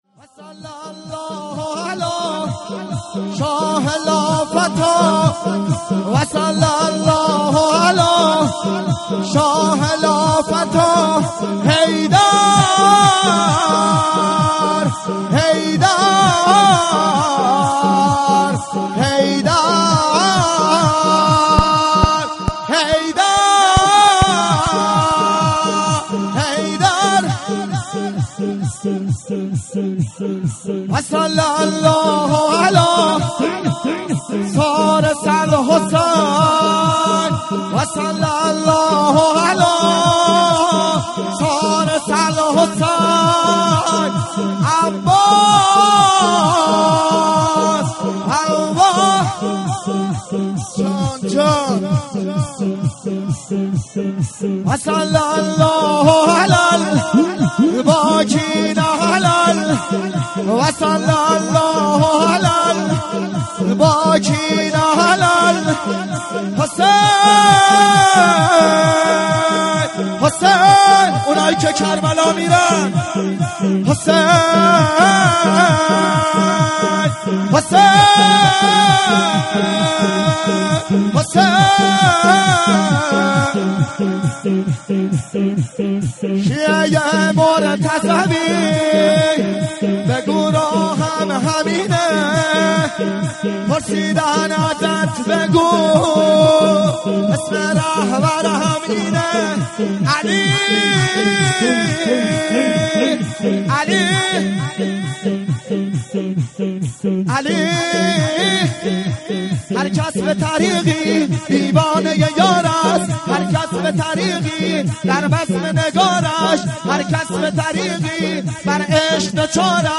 مراسم هفتگی 94/09/01
شور